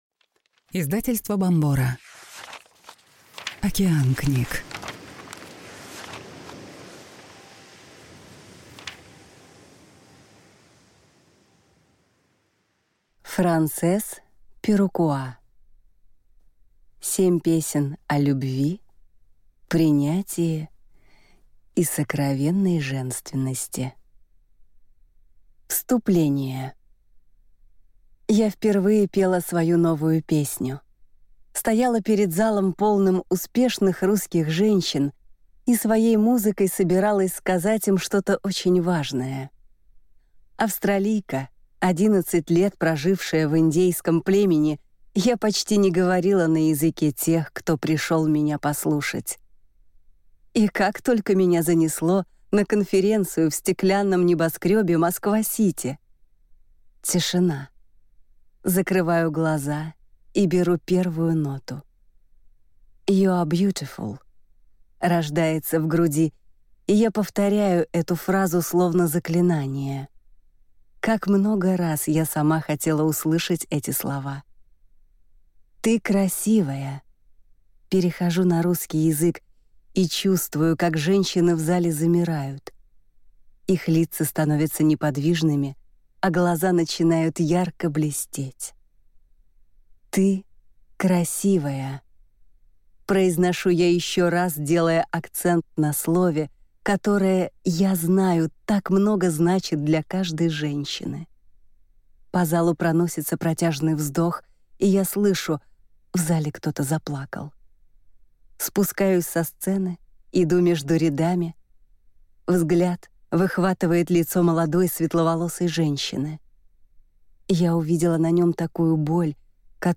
Аудиокнига Перукуа. 7 песен о любви, принятии и сокровенной женственности | Библиотека аудиокниг